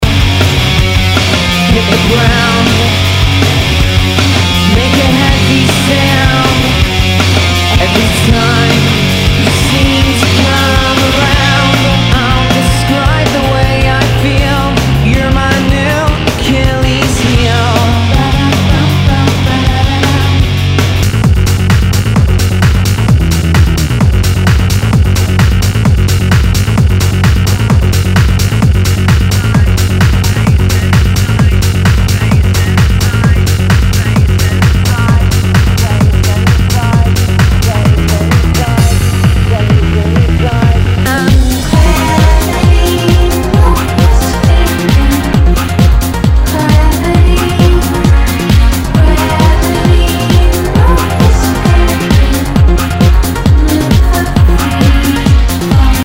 HOUSE/TECHNO/ELECTRO
ナイス！インディー・ロック / ハウス！！